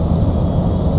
turbo_use.wav